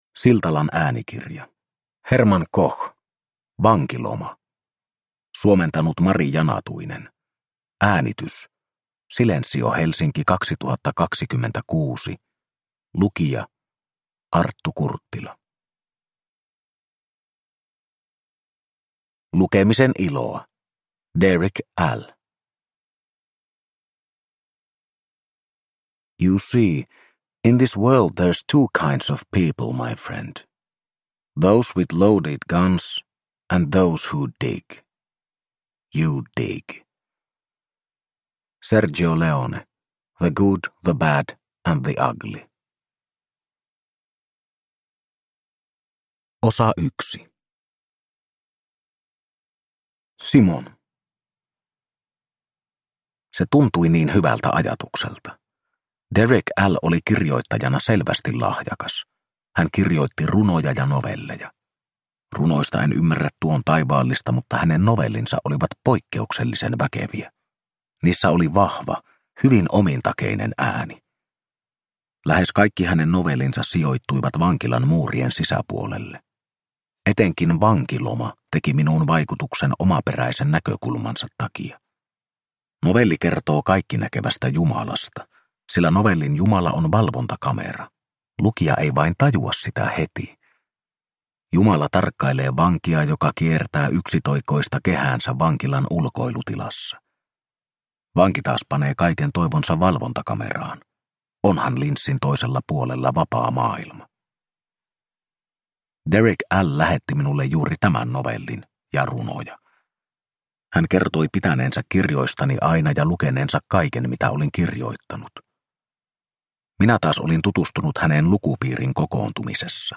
Vankiloma – Ljudbok